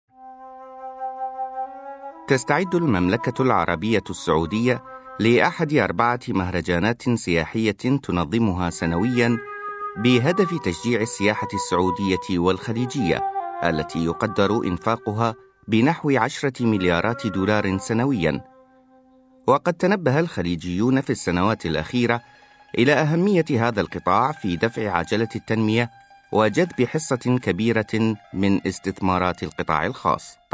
Arabic (Iraq), Middle Eastern, Male, 30s-50s